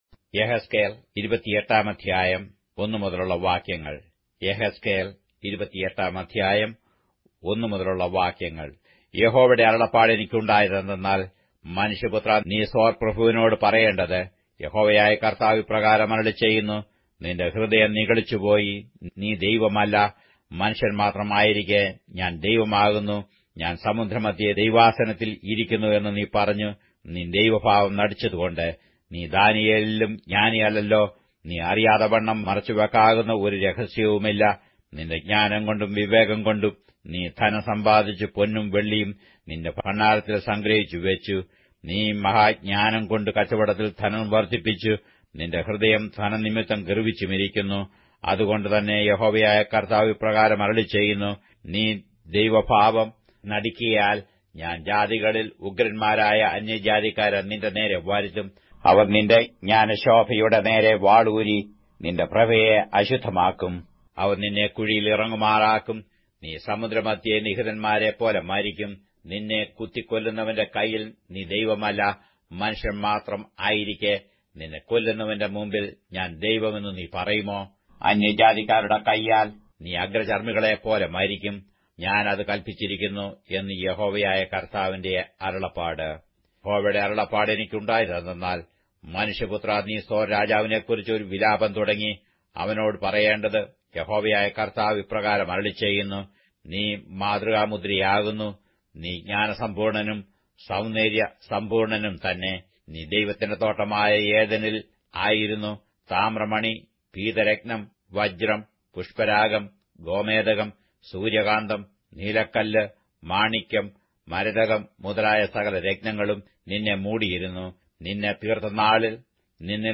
Malayalam Audio Bible - Ezekiel 42 in Ocvbn bible version